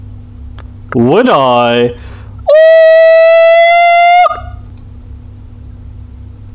monkey_1.mp3